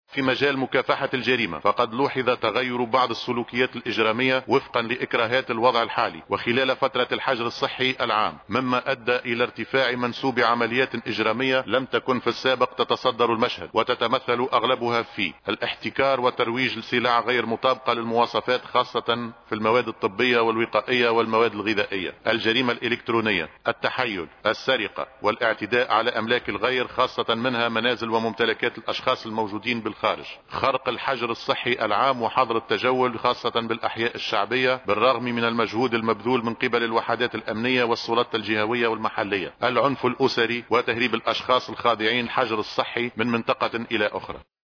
كما أشار المشيشي، في كلمة له خلال جلسة حوار خلية الأزمة بمجلس نواب الشعب، إلى أن من بين الجرائم التي ارتفع منسوبها خلال هذه الفترة، الاحتكار وترويج السلع غير المطابقة للمواصفات، وخاصة منها المواد الطبية والغذائية، والجرائم الالكترونية والتحيّل والسرقة والاعتداء على أملاك الغير وخاصة منازل الأشخاص المتواجدين بالخارج، وانتهاك الحجر الشامل وحظر التجول، خصوصا بالأحياء الشعبية، والعنف الاسري وتهريب الاشخاص الخاضعين للحجر من منطقة إلى أخرى.